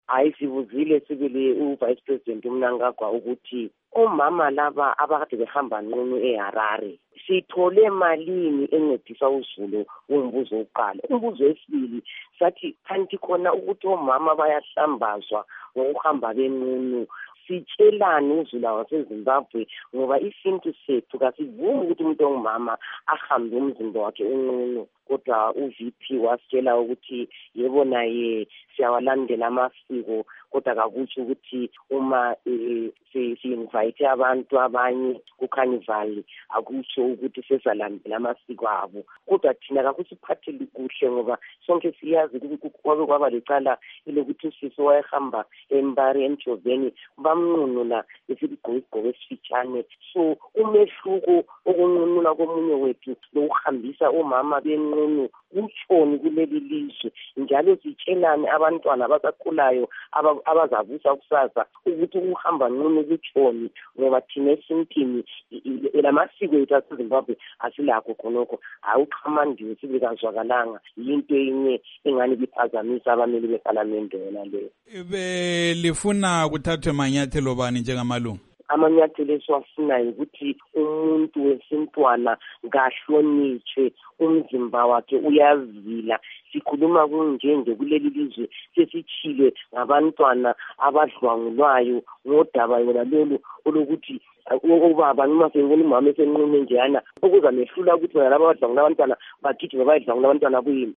Ingxoxo loNkosazana Thabitha Khumalo